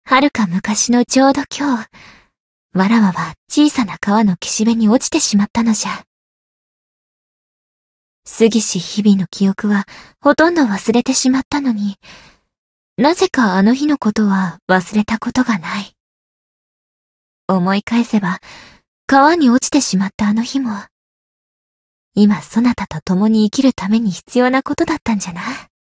灵魂潮汐-蕖灵-人偶生日（相伴语音）.ogg